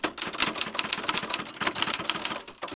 typing0a.wav